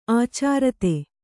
♪ ācārate